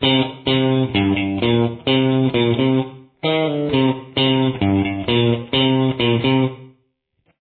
• This is a very easy guitar riff to play.